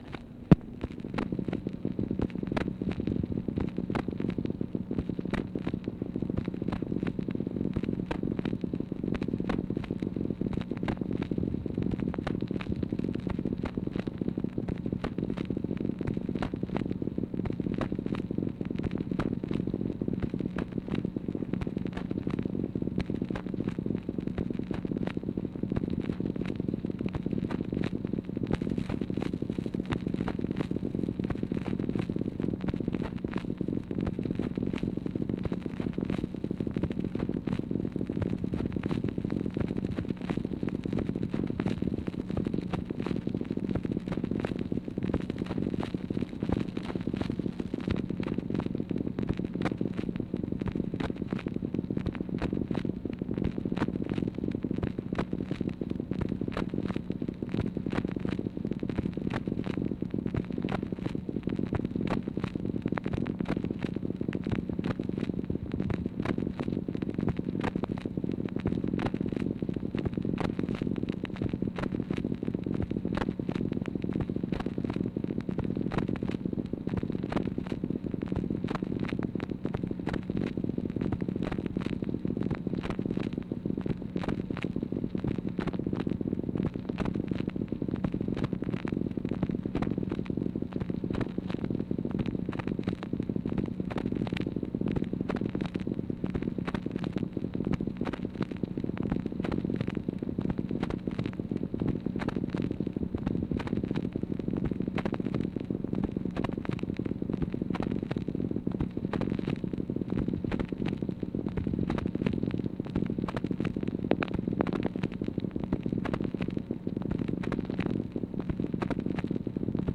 MACHINE NOISE, March 18, 1964
Secret White House Tapes | Lyndon B. Johnson Presidency